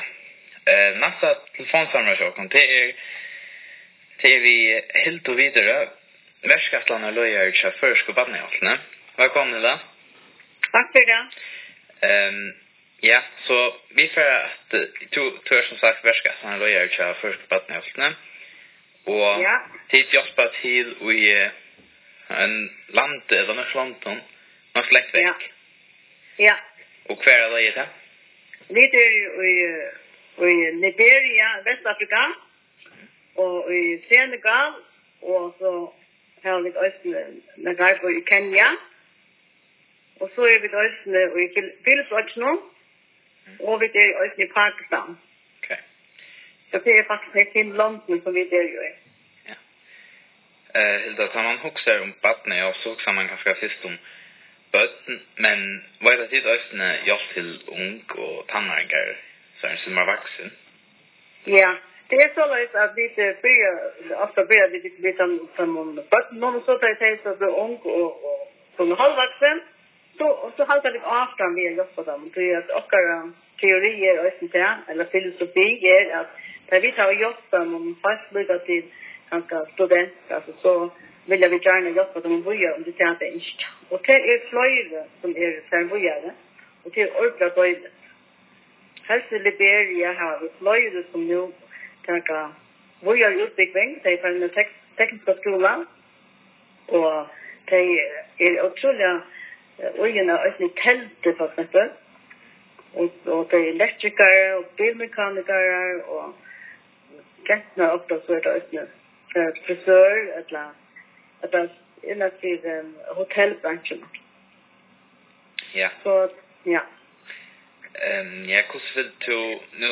Hoyr telefonsamrøðuna her: